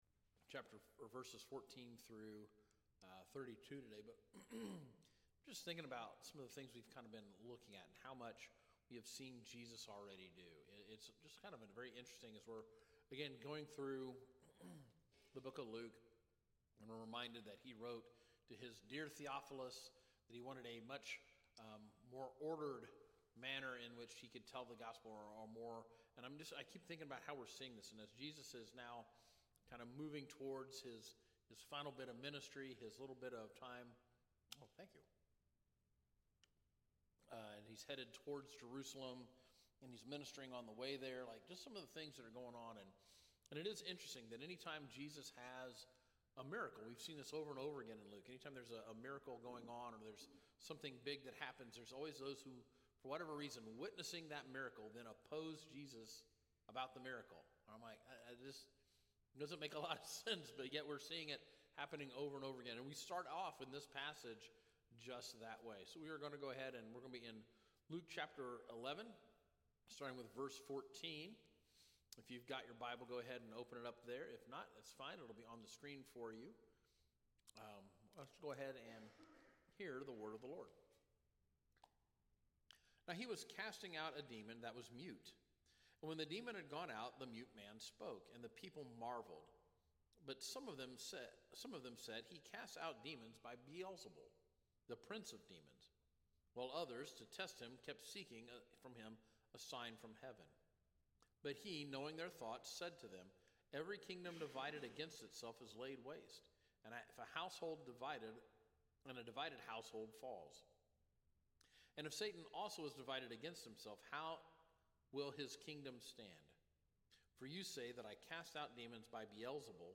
Sermons by Calvary Heights Baptist Church